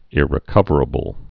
(ĭrĭ-kŭvər-ə-bəl)